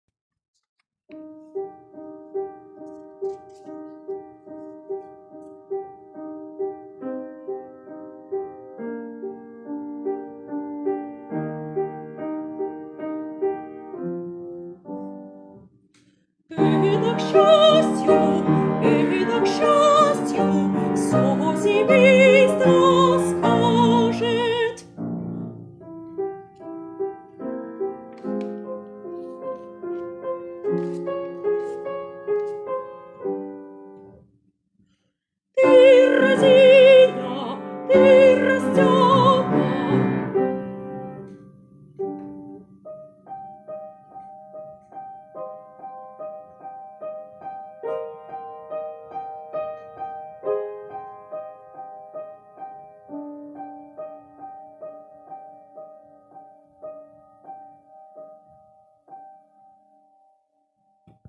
альт